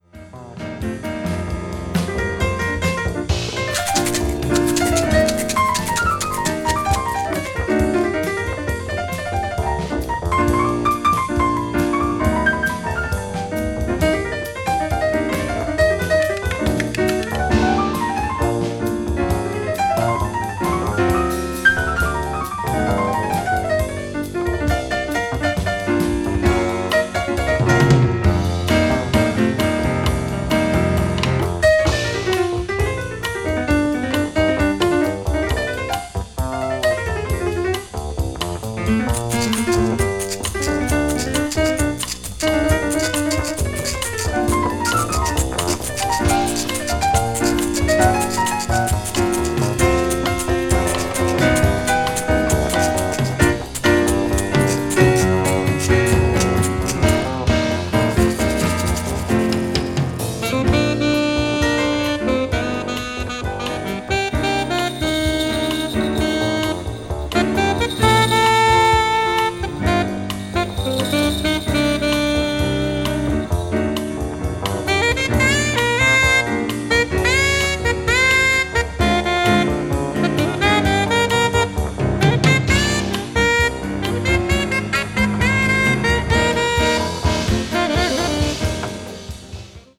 The quartet plays orthodox bop and modal jazz
contemporary jazz   ethnic jazz   modal jazz   post bop